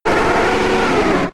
Cri de Feunard K.O. dans Pokémon X et Y.